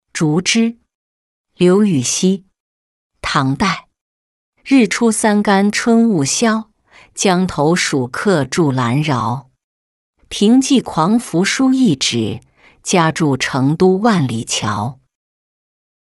竹枝-音频朗读